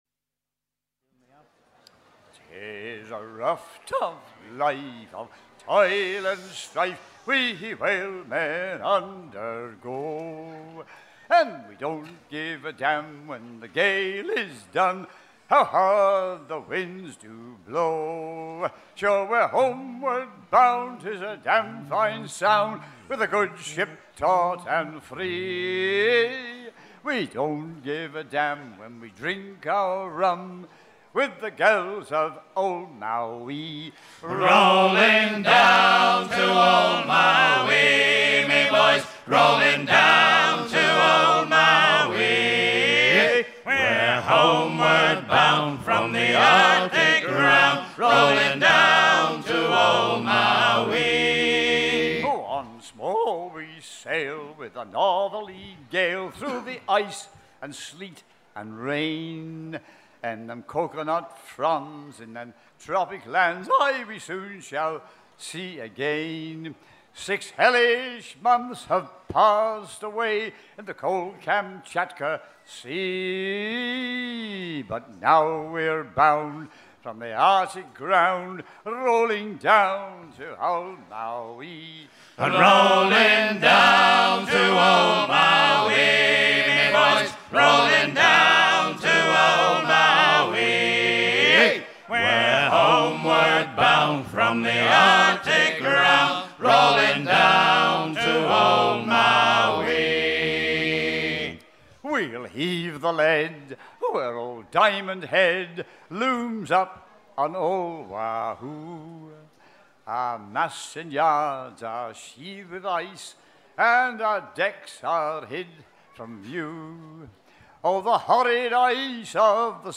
chanson de baleiniers
Pièce musicale éditée